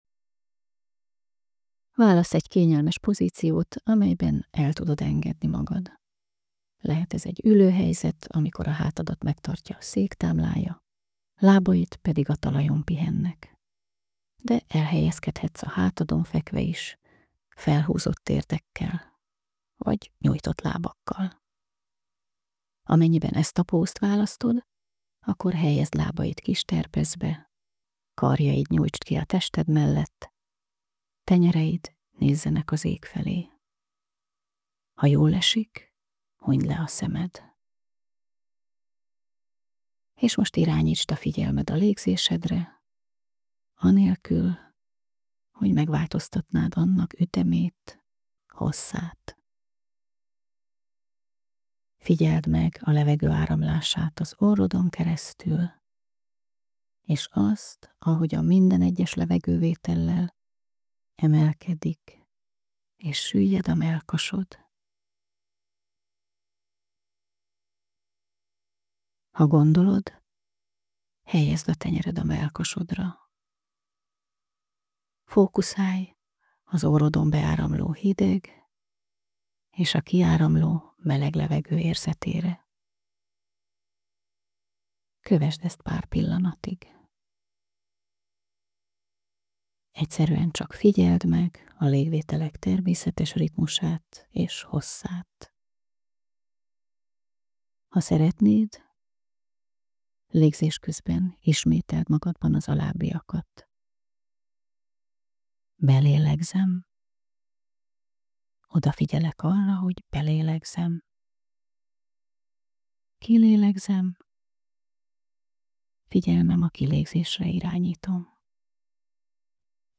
Relaxációs technikák
Ellentétben más relaxációs anyagokkal nincs hozzá andalító aláfestő zene vagy bálnák éneke 🐋